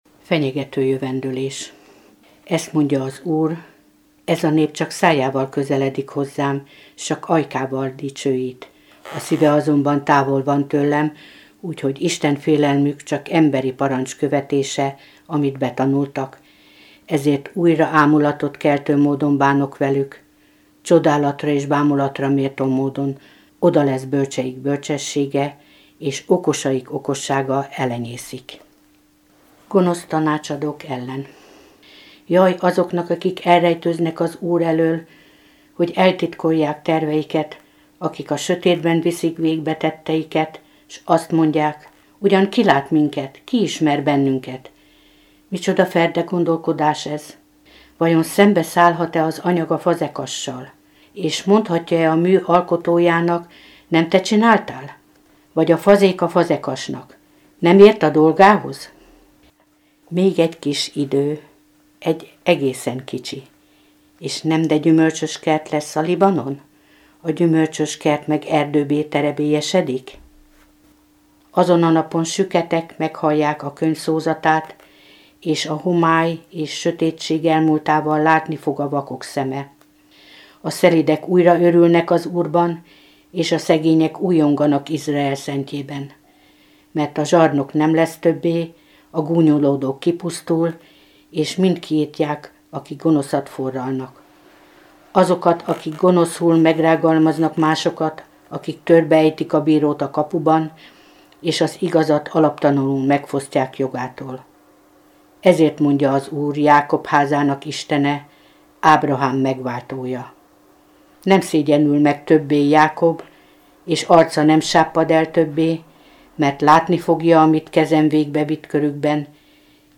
Felolvasók: a Szeged-Tarjánvárosi Szent Gellért Plébánia hívei
A felvétel a Szent Gellért Plébánián készült 2024. augusztusában